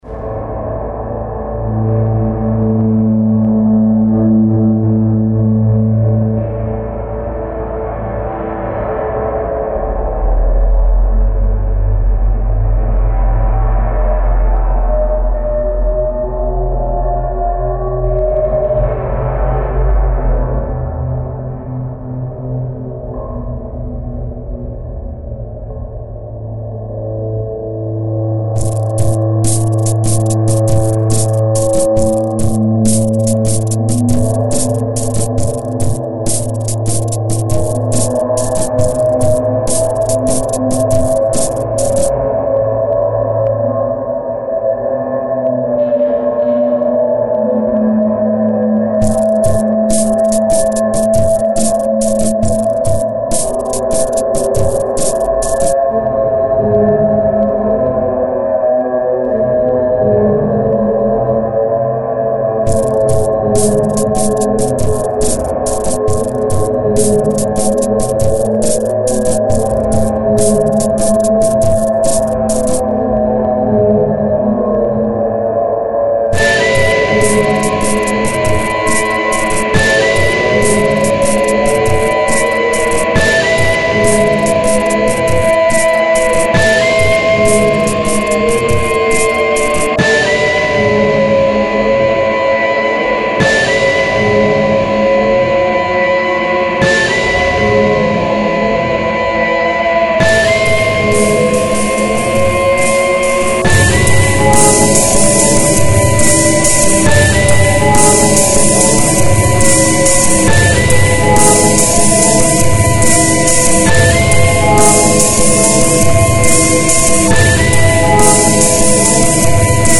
samples, enregistrements, production & mixage.